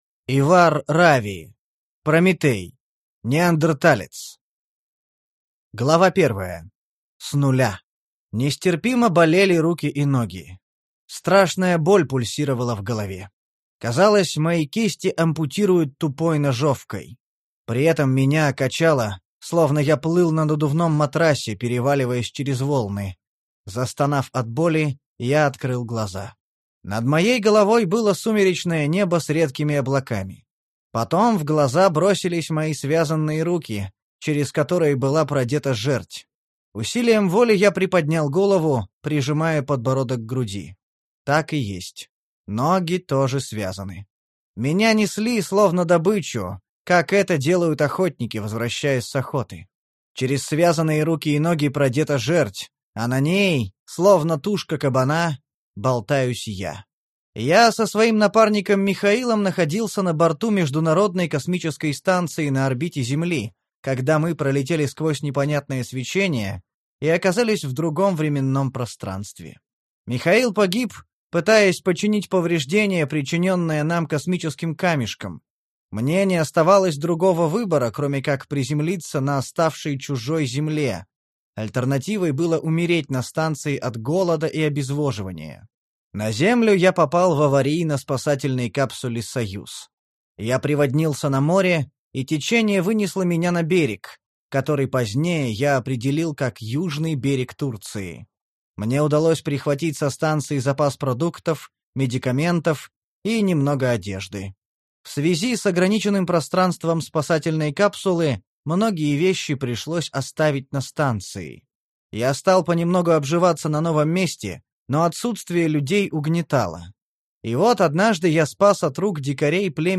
Аудиокнига Прометей: Неандерталец | Библиотека аудиокниг